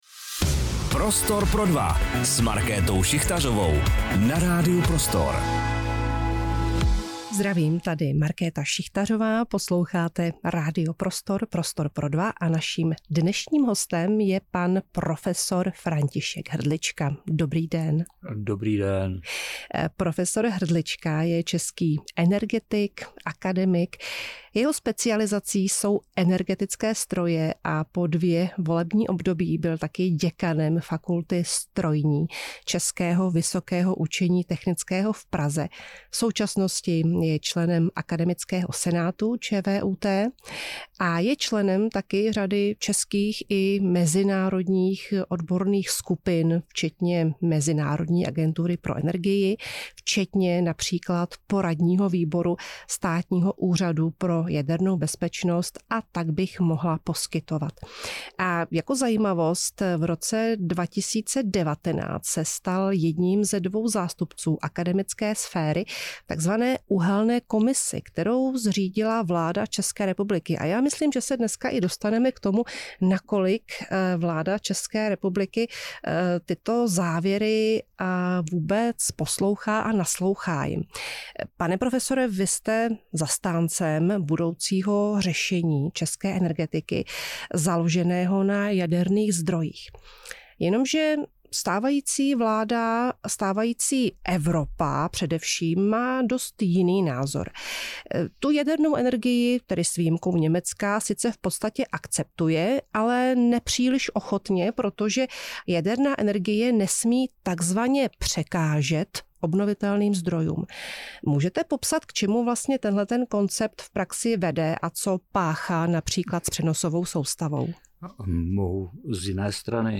Rozhovor moderovala Markéta Šichtařová.